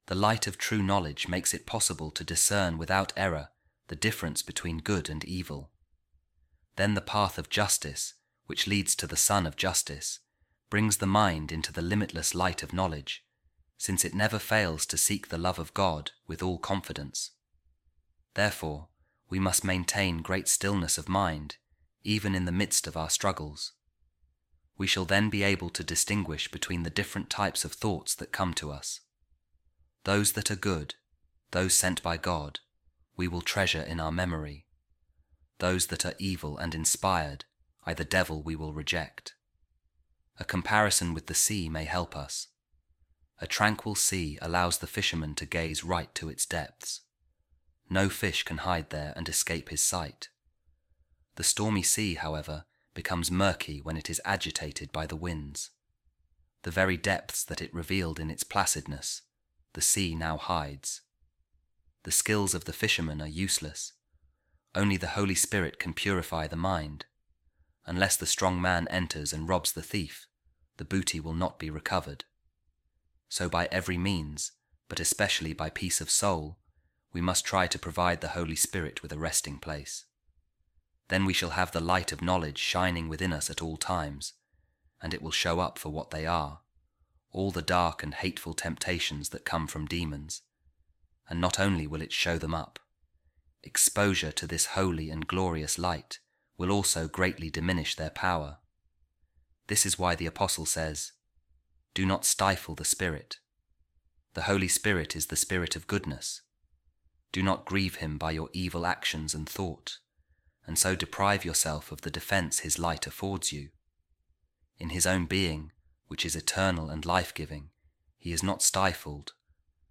Divine Office | Office Of Readings